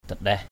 /d̪a-ɗɛh/ 1.
dandaih.mp3